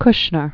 (kshnər), Tony Born 1956.